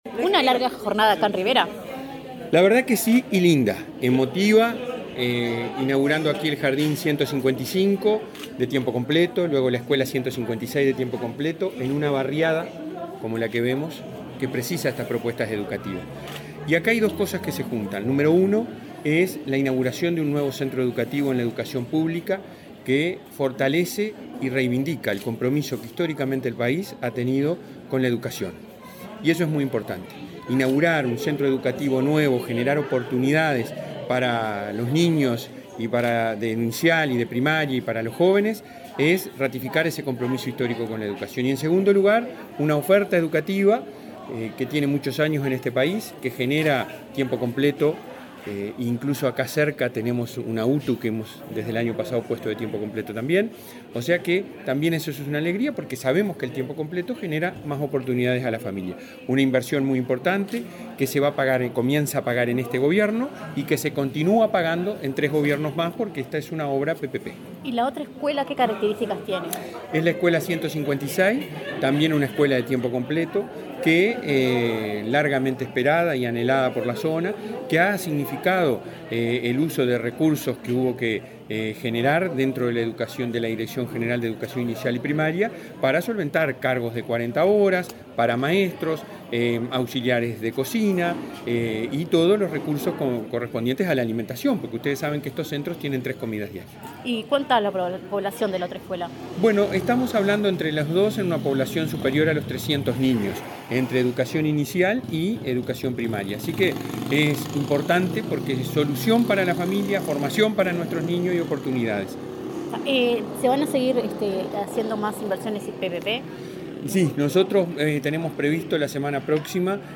Entrevista al presidente de ANEP, Robert Silva